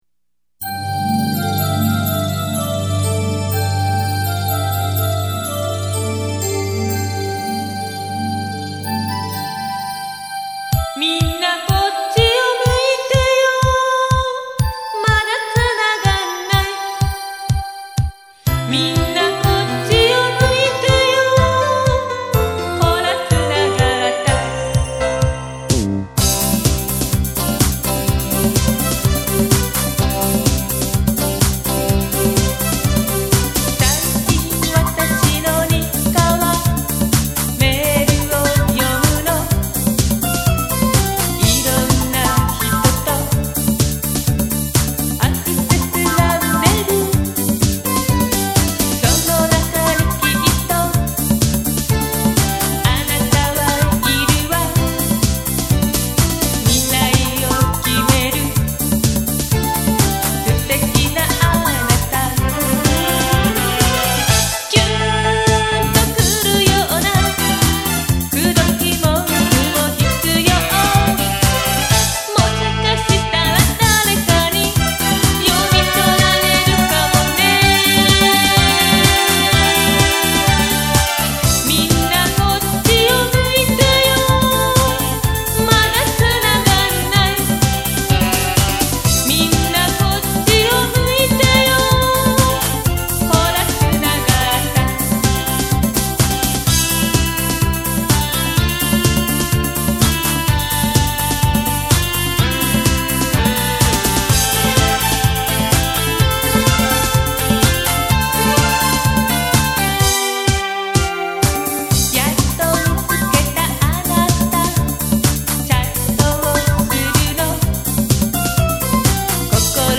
フルコーラス　　ＭＰ３ファィル・・・3.15ＭＢ